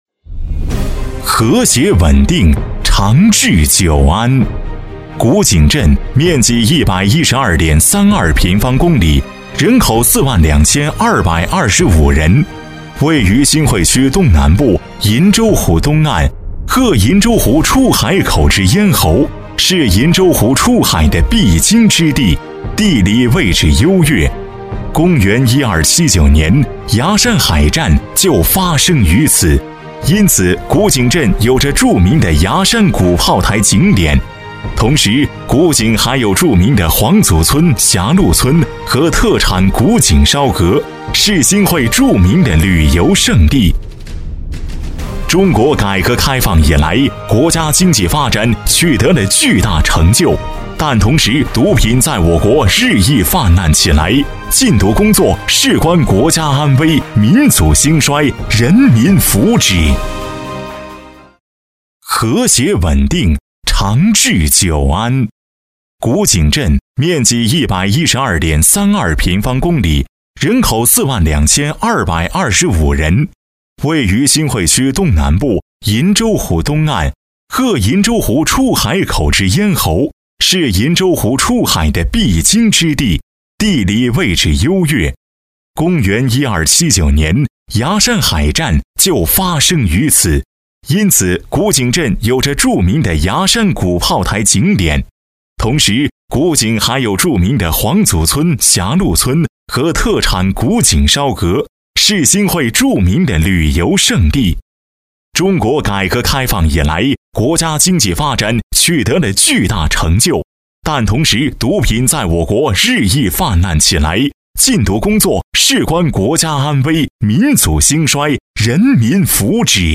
新生代配音员，声音有磁性，适合录制普通专题片稿件。